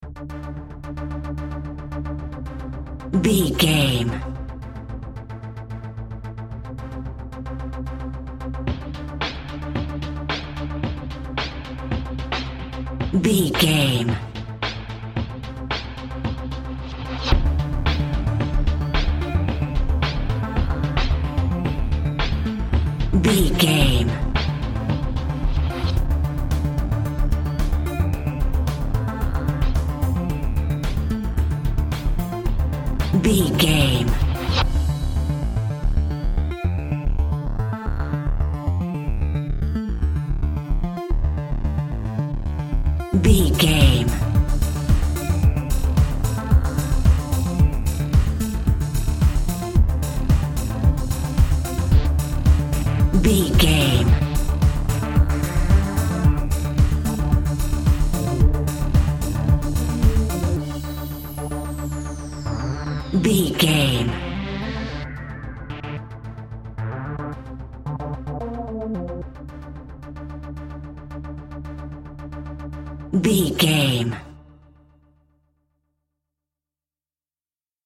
Aeolian/Minor
D
driving
uplifting
hypnotic
drum machine
synthesiser
energetic
Synthpop
synth drums
synth leads
synth bass